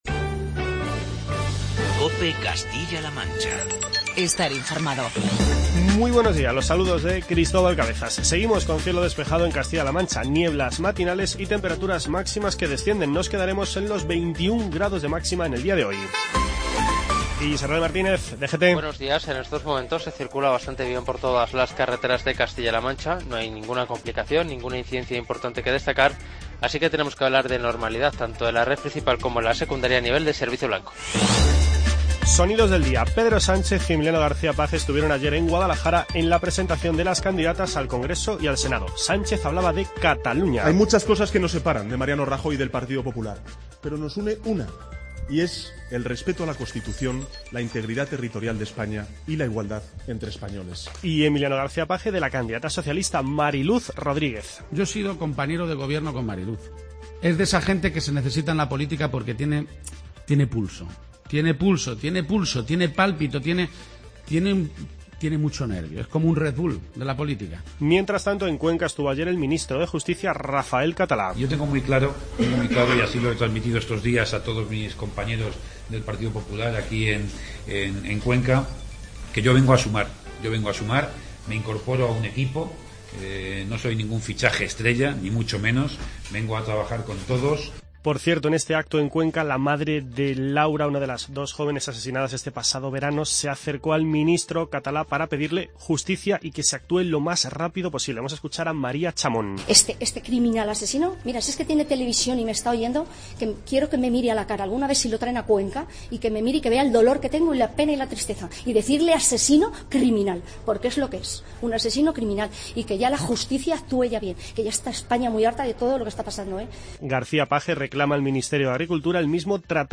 Informativo regional y provincial
Escuchamos, entre otros, los testimonios de Pedro Sánchez, Emiliano García-Page y Vicente Tirado